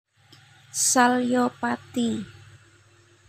Contoh pengucapan